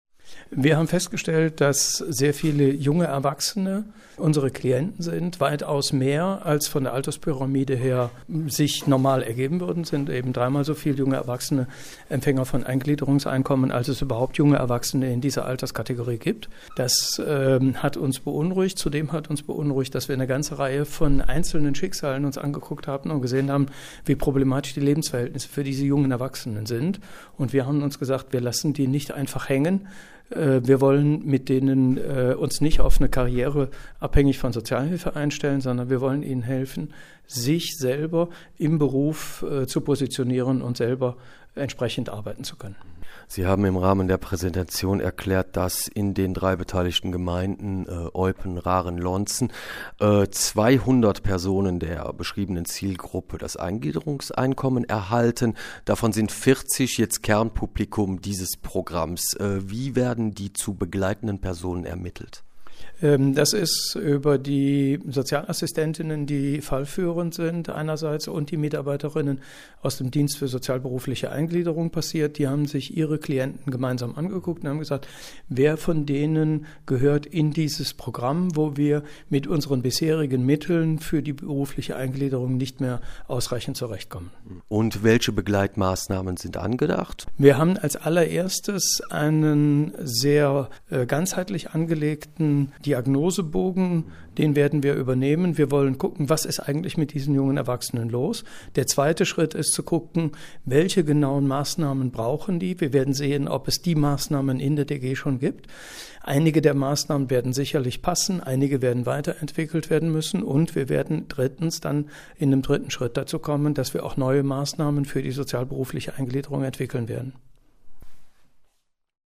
sprach darüber mit dem Präsidenten des ÖSHZ in Raeren, Ulrich Deller: